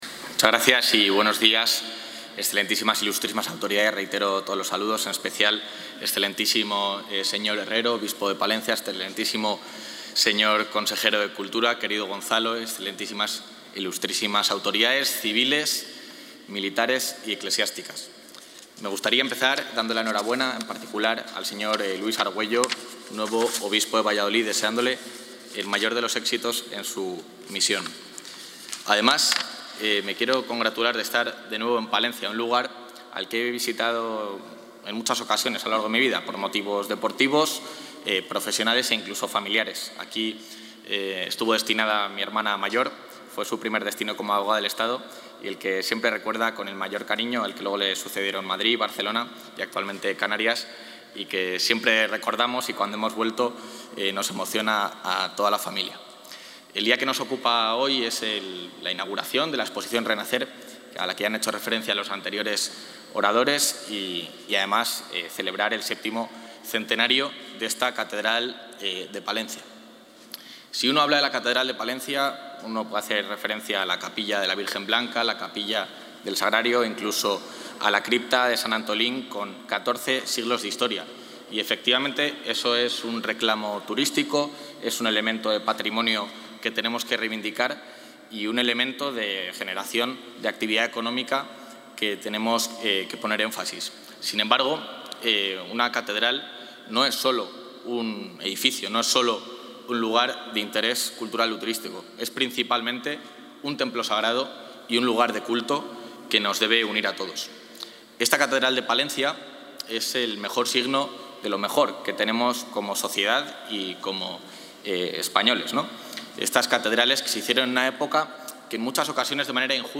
Intervención del vicepresidente de la Junta.
El vicepresidente de la Junta, Juan García-Gallardo, ha acudido hoy a la inauguración de la exposición ‘Renacer’ de la Catedral de Palencia, una muestra concebida con el objetivo de redescubrir al templo palentino como foco de interés cultural y promocionarlo a escala nacional durante su VII centenario.